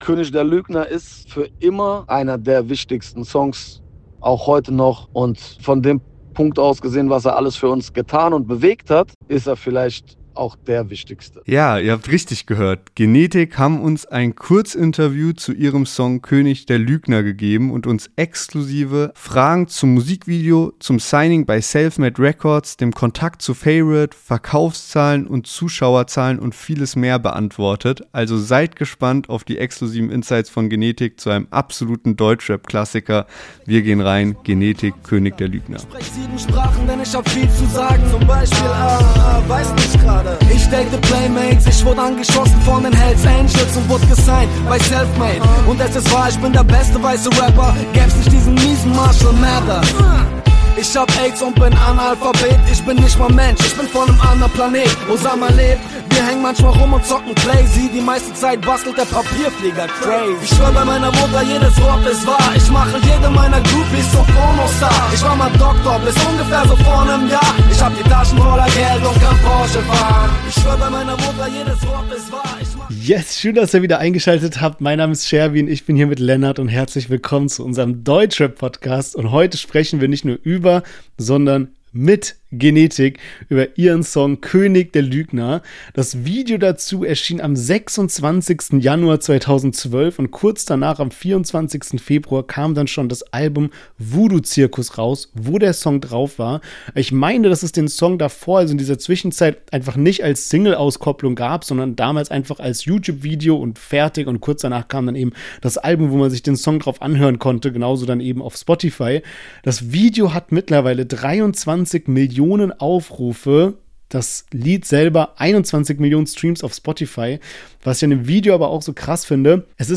Beschreibung vor 2 Monaten Vom Signing bei Selfmade Records über tausende Plattenverkäufe zum ersten Live-Auftritt als Vorband für den Wu-Tang Clan in nur wenigen Monaten: Genetikk geben uns im exklusiven Kurz-Interview Antworten auf die Mythen ihrer Anfangszeit!